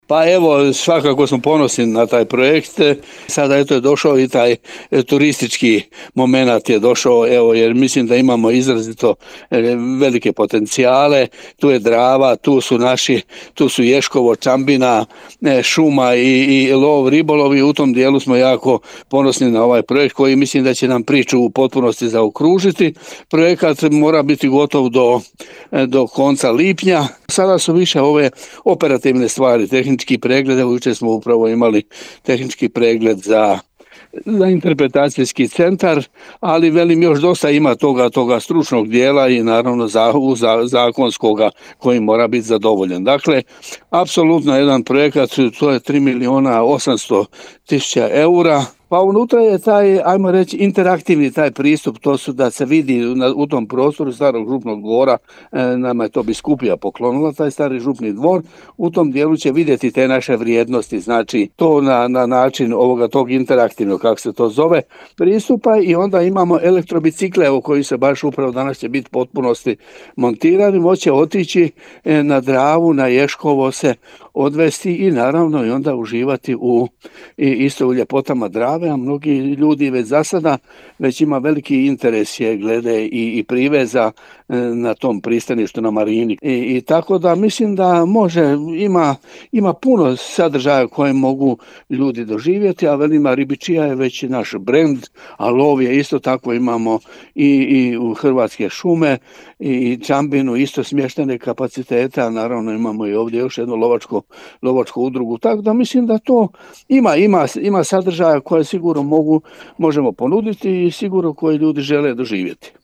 U emisiji „Susjedne općine” gostovao je načelnik Općine Gola dr. Stjepan Milinković koji je tom prilikom najavio završetak radova na Interpretacijskom centru „Bolenov dravski put”.